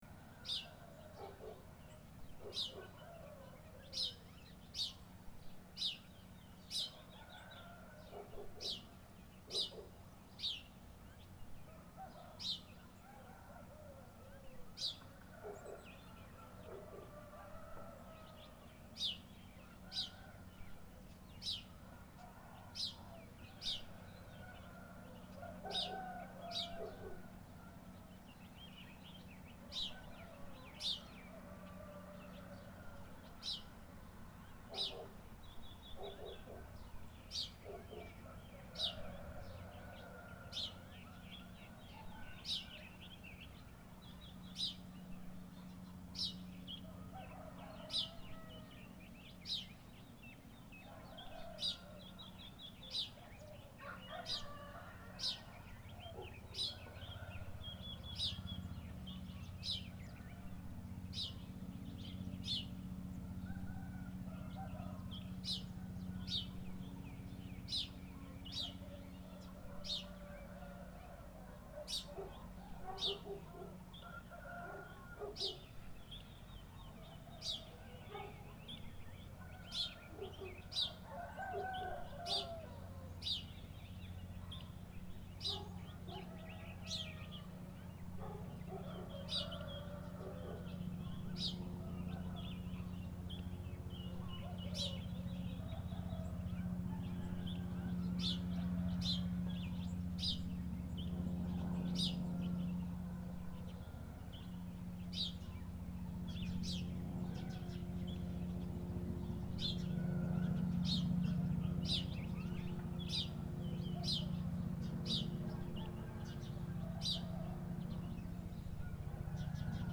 Quelques exemples de caca sonore en faisant le moins de bruit possible (les aéronefs passent très haut et ne sont pas à fond).
L'environnement naturel et même résidentiel tout autour étant calme, par contraste même un ULM volant à plus de 1000m s'entend fortement.**